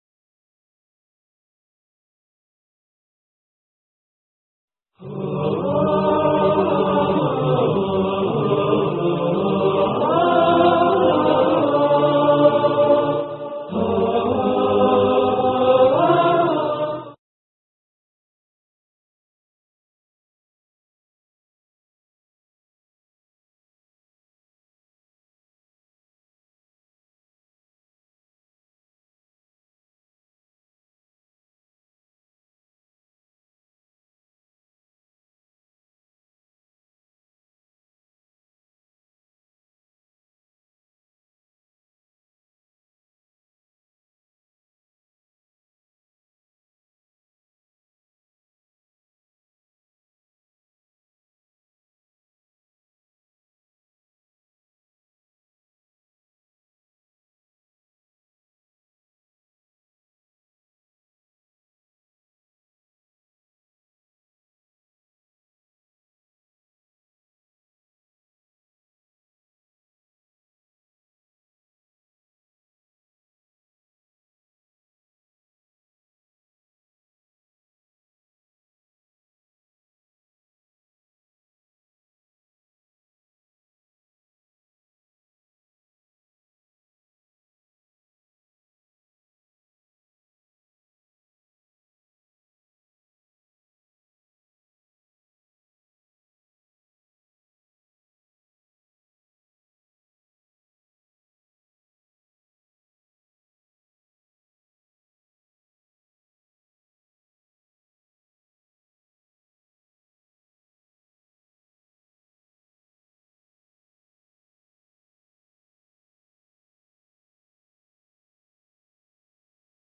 خطبة يوم عرفة